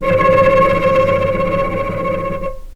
vc_trm-C#5-pp.aif